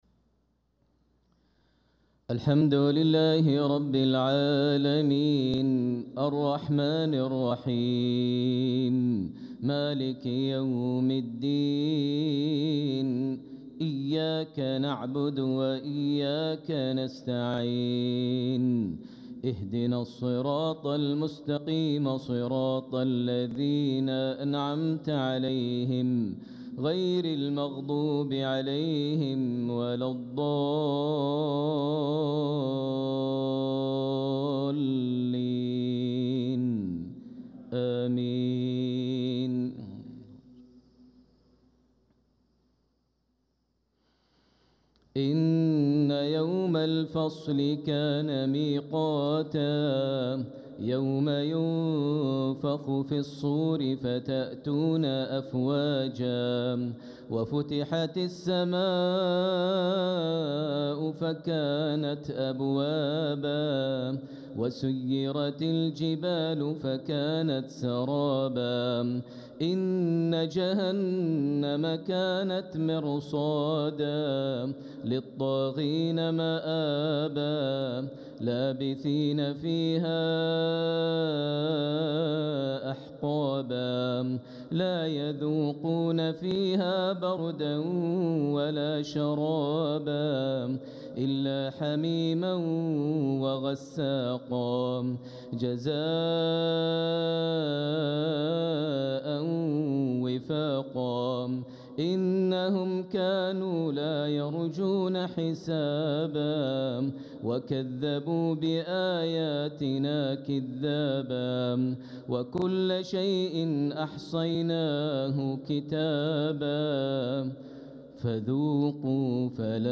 صلاة المغرب للقارئ ماهر المعيقلي 6 ربيع الأول 1446 هـ
تِلَاوَات الْحَرَمَيْن .